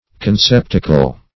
Conceptacle \Con*cep"ta*cle\, n. [L. conceptaculum, fr.